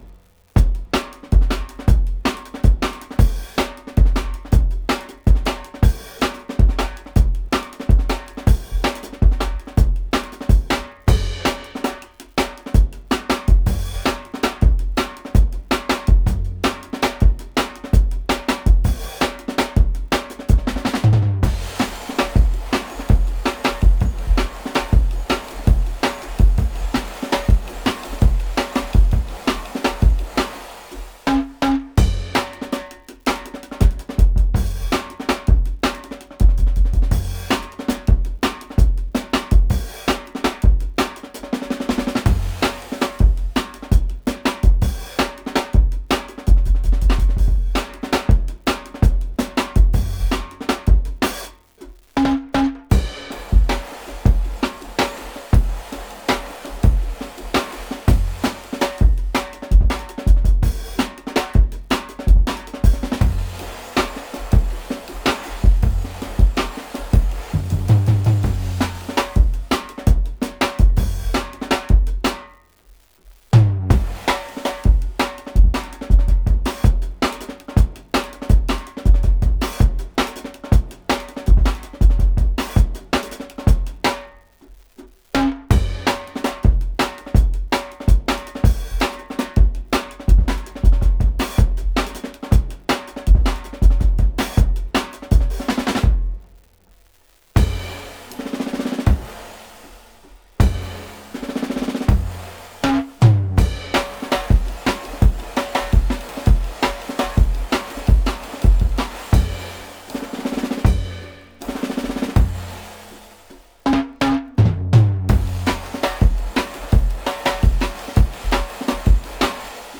Index of /4 DRUM N BASS:JUNGLE BEATS/BEATS THAT WILL RATTLE YOUR TEETHS